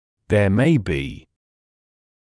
[ðeə meɪ biː] [зэа мэй биː] может быть